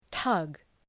Προφορά
{tʌg}